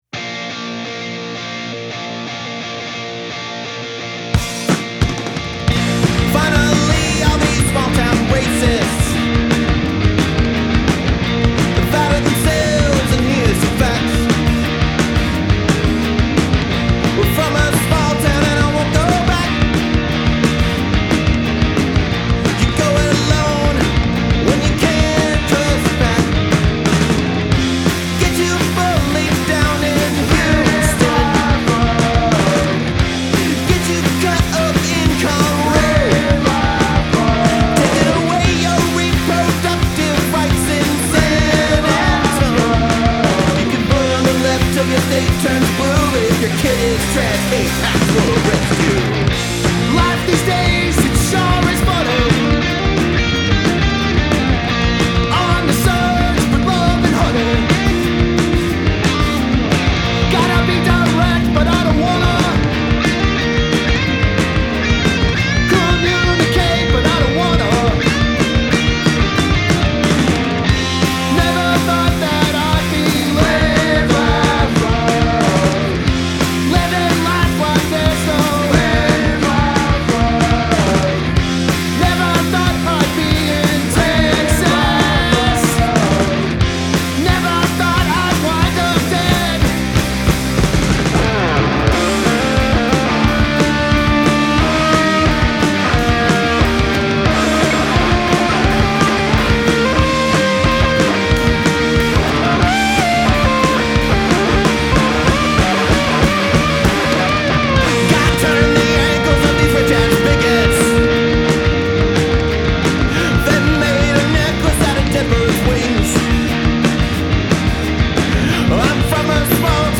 Austin's slop-punk mavens